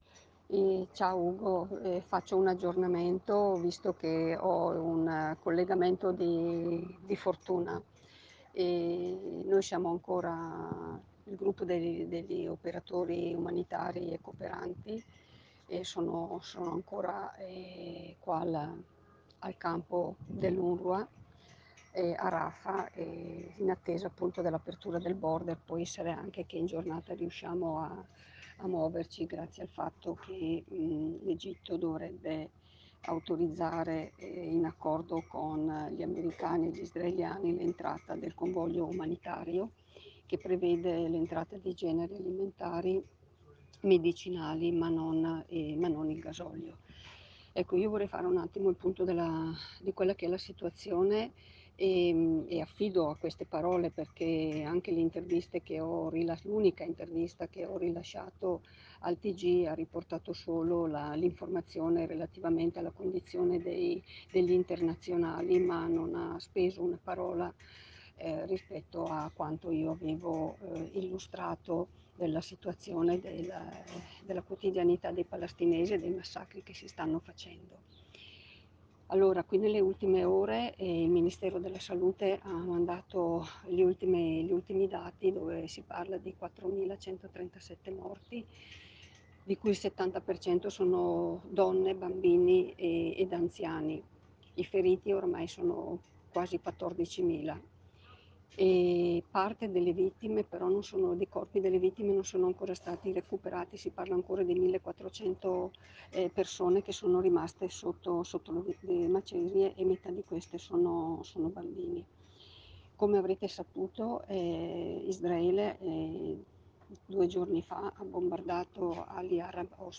Riceviamo dall’associazione Gazzella, impegnata da sempre nella solidarietà con il popolo palestinese, l’agghiacciante testimonianza audio di una volontaria sui numeri della mattanza, sull’inadeguatezza dell’informazione main stream italiana per comprendere la situazione e sulla tragedia al confine tra Gaza e l’Egitto dove sembra che oggi parzialmente sia stato consentito il passaggio di una piccola parte degli aiuti umanitari indispensabili per la sopravvivenza delle persone costrette alla fuga, superstiti dei bombardamenti israeliani tutt’ora in corso sulla Striscia di Gaza.